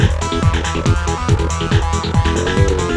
Today they are working on a techno-trance track.
The kick and the bass have a rather "deep"
created to be played in loops.